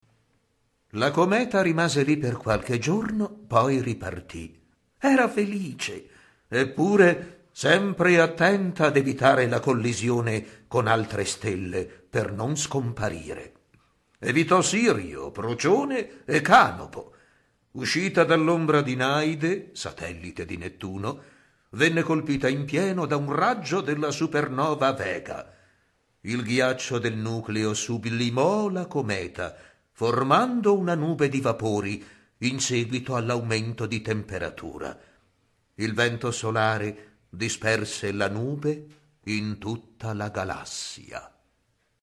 AUDIO narratore